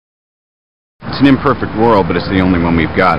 描述：玩摩擦音乐
声道立体声